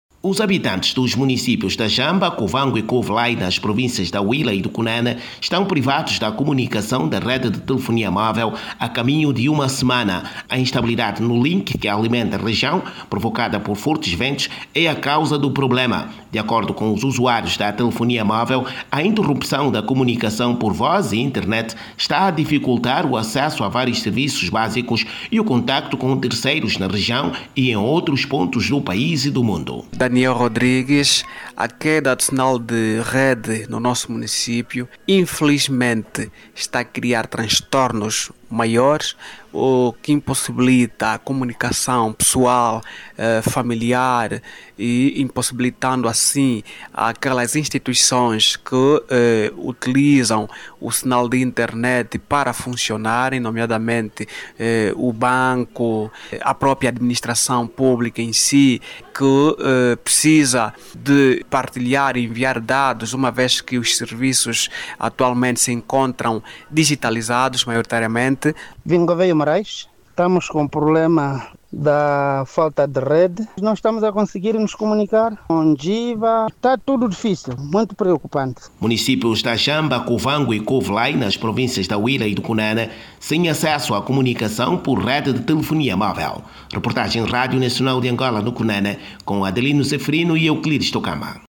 As comunicações por via telefonia móvel e internet estão inoperantes, a mais de uma semana nos municípios da Jamba, Cuvango e Cuvelai entre as províncias da Huíla e Cunene. A situação preocupa os cidadãos que falam em vários constrangimentos, principalmente para os que trabalham com o sistema de comunicação e internet. Saiba mais dados no áudio abaixo com o repórter